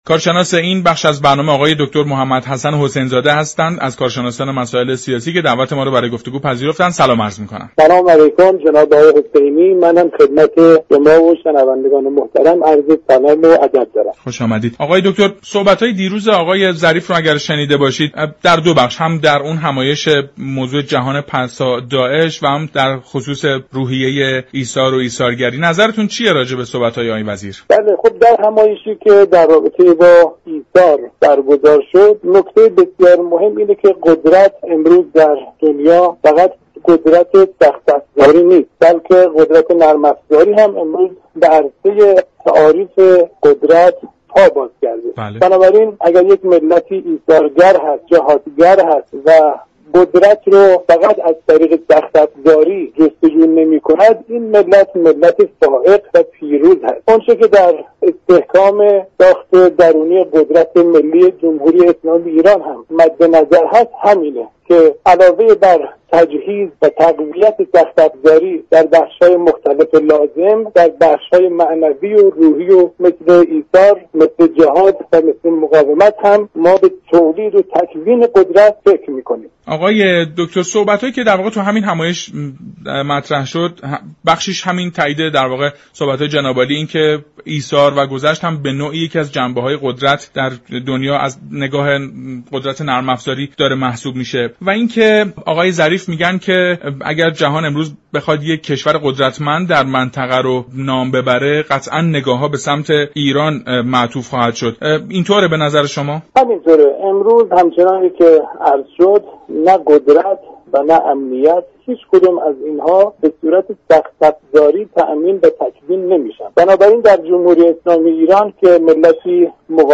یك كارشناس مسائل سیاسی در گفت و گو با جهان سیاست گفت: جمهوری اسلامی ایران در كنار قدرت سخت افزاری فرهنگ جهادی و ایثاری را در خود پرورش می دهد